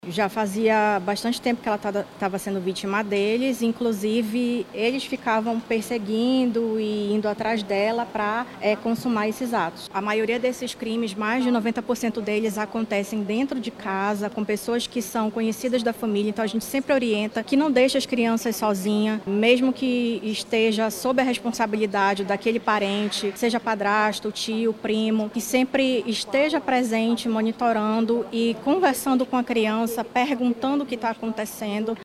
SONORA02_DELEGADA-1.mp3